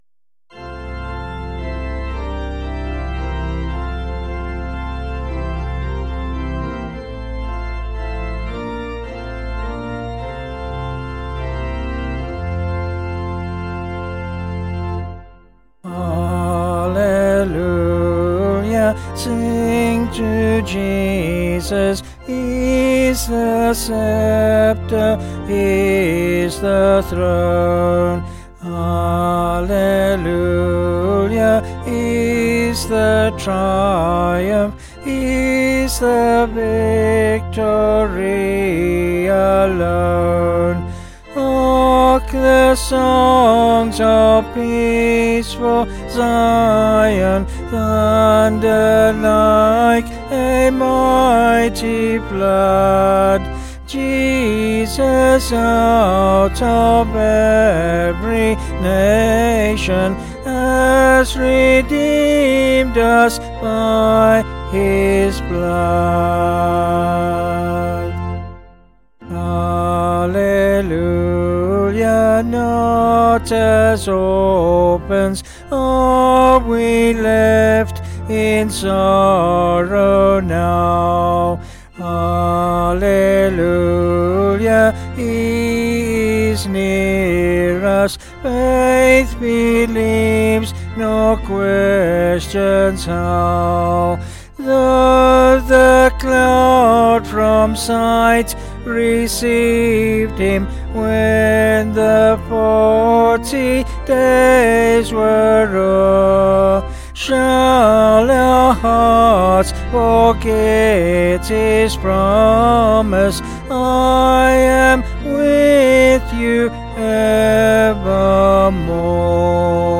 Vocals and Organ   707.5kb Sung Lyrics